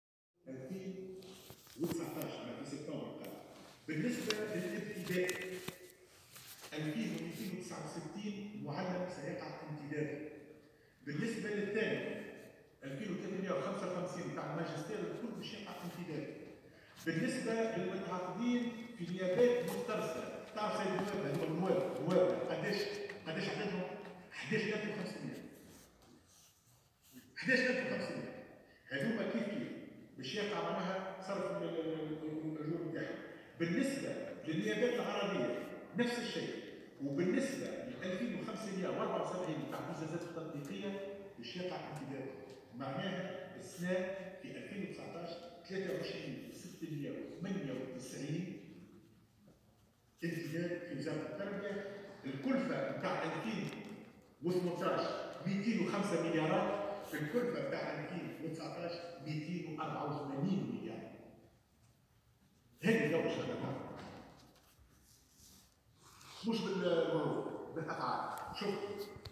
قال وزير التربية حاتم بن سالم خلال جلسة استماع بمجلس نواب الشعب اليوم الاثنين واكبها مراسل "الجوهرة أف أم"، إن وزارته وضعت خطة لانتداب 23698 إطار تدريس بالنسبة للسنة الدراسية 2019.